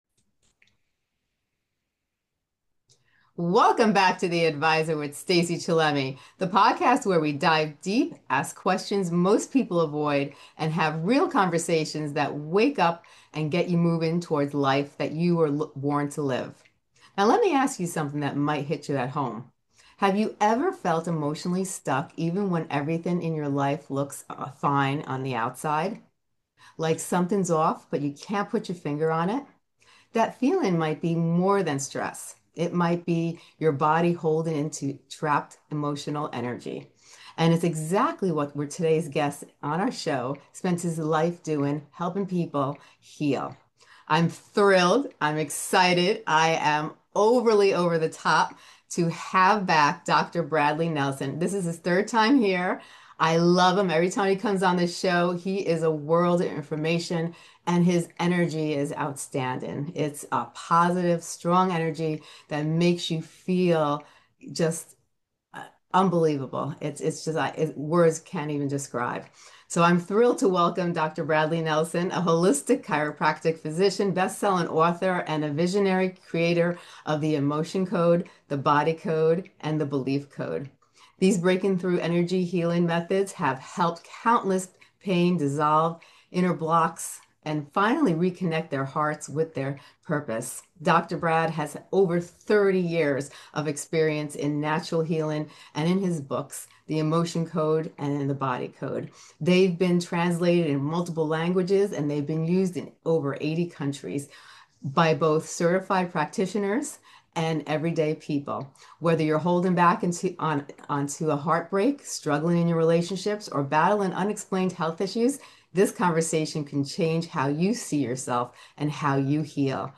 Love insightful and life-changing interviews?